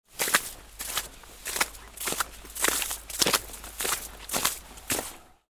Footsteps-on-wet-snow-and-slush-sound-effect.mp3